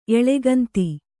♪ eḷeganti